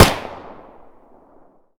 3098b9f051 Divergent / mods / Boomsticks and Sharpsticks / gamedata / sounds / weapons / l85_m3 / l85_10.ogg 49 KiB (Stored with Git LFS) Raw History